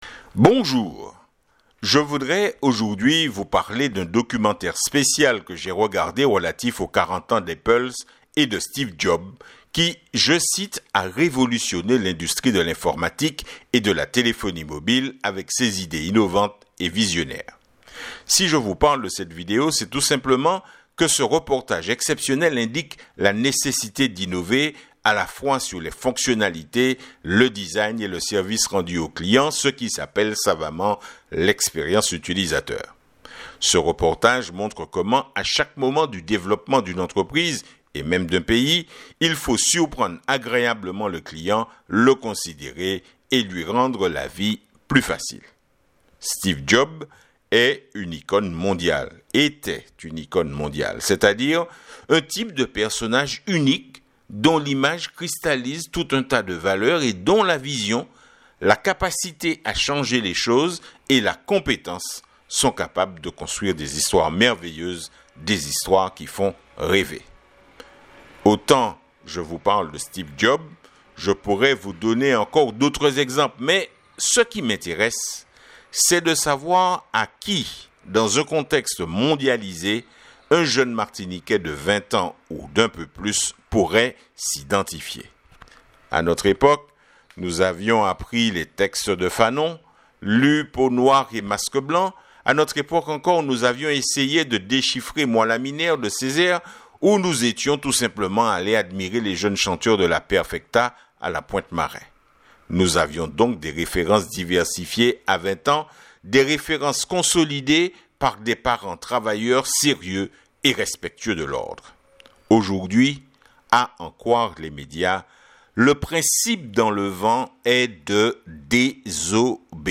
Pour écouter l’éditorial cliquez sur le bouton de démarrage